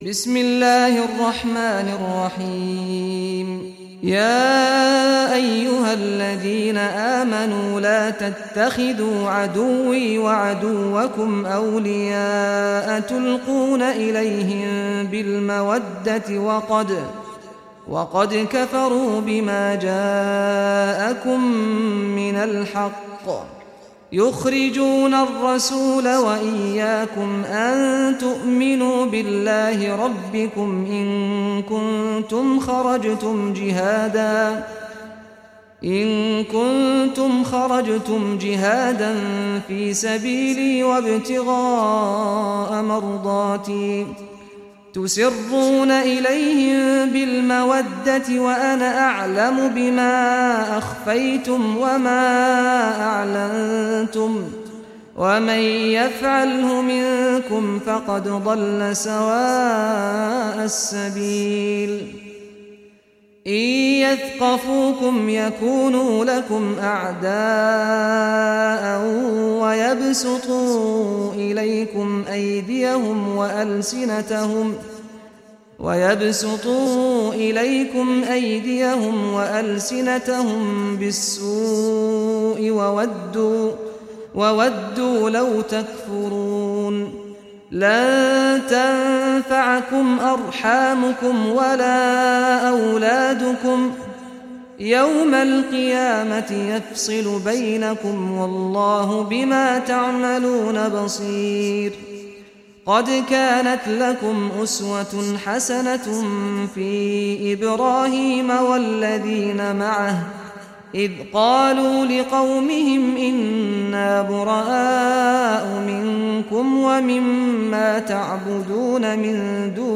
Surah Mumtahanah Recitation by Sheikh Saad Ghamdi
Surah Al-Mumtahanah. listen or play online mp3 tilawat / recitation in Arabic in the beautiful voice of Sheikh Saad al Ghamdi.